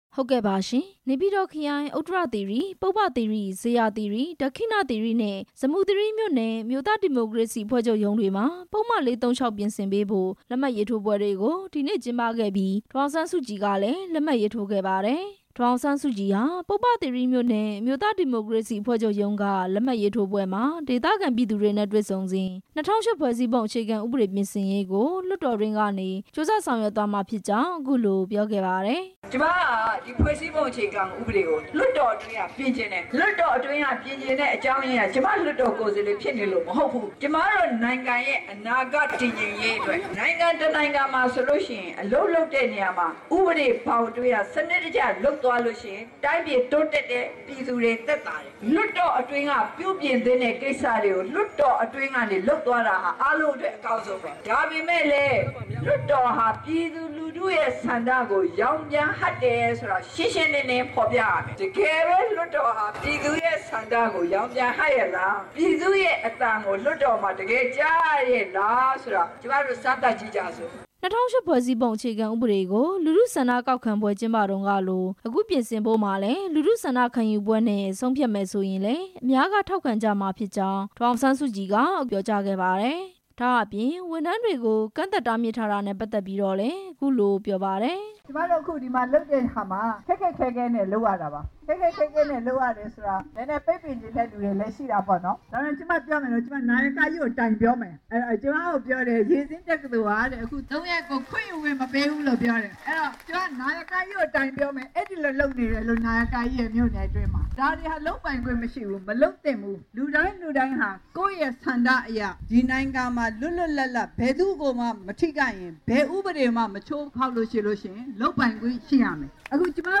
ဒီနေ့ နေပြည်တော်ခရိုင် ပုဗ္ဗသီရိမြို့နယ်မှာ ကျင်းပတဲ့ ပုဒ်မ ၄၃၆ ပြင်ဆင်ရေး လက်မှတ်ရေးထိုးပွဲမှာ ဒေသခံတွေ နဲ့တွေ့ဆုံစဉ် ဒေါ်အောင်ဆန်းစုကြည်က အခုလို ပြောခဲ့ တာပါ။